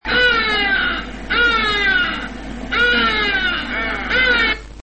głosy
ibis czczonymp319 kb